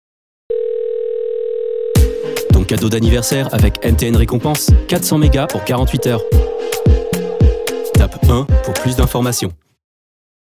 MTN fast_annonce 2_kizz daniel_avec bip.wav